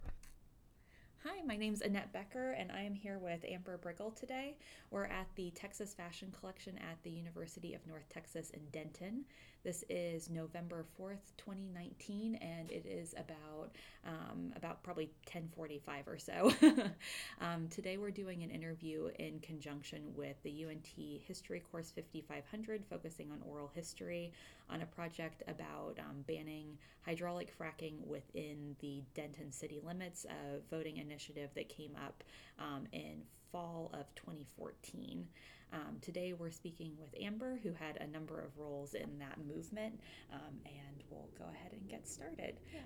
Introduction to interview, with identifying information